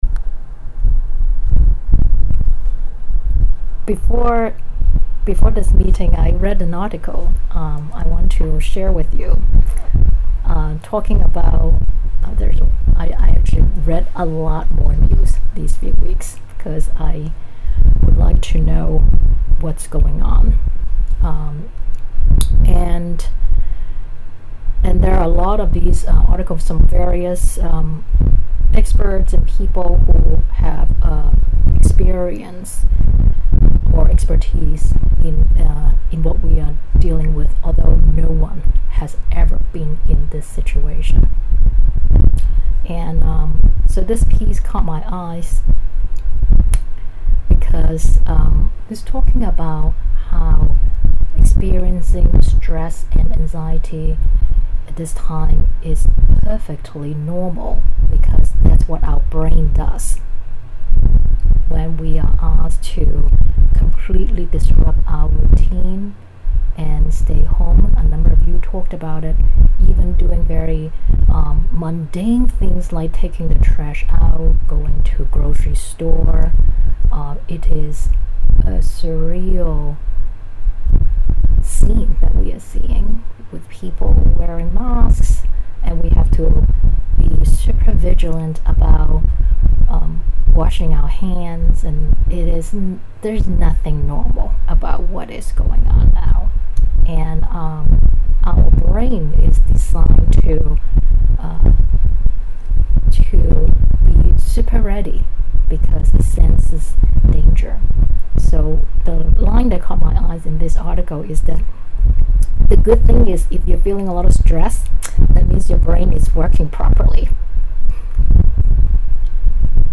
This talk was given at the Weekly Online Dharma Practice Gathering on April 10, 2020.